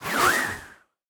Minecraft Version Minecraft Version latest Latest Release | Latest Snapshot latest / assets / minecraft / sounds / mob / breeze / idle_air2.ogg Compare With Compare With Latest Release | Latest Snapshot
idle_air2.ogg